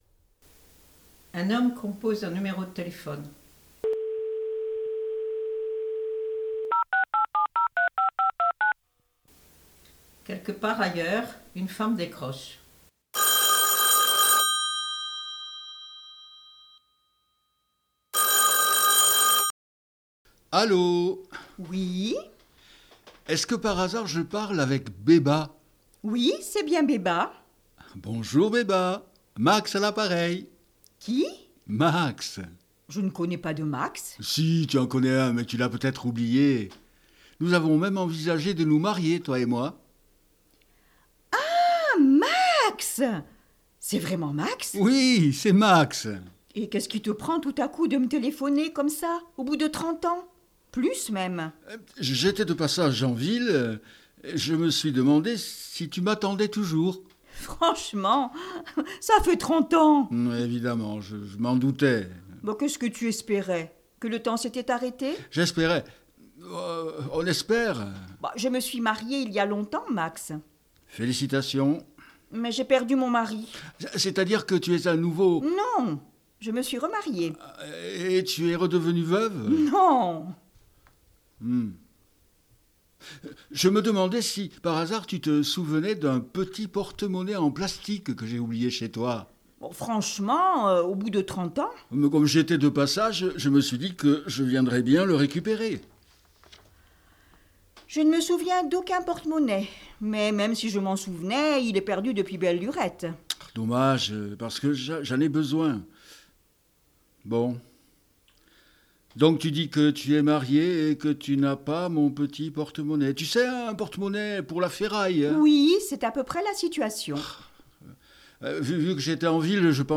lu par